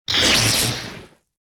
repair5.ogg